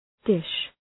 Shkrimi fonetik {dıʃ}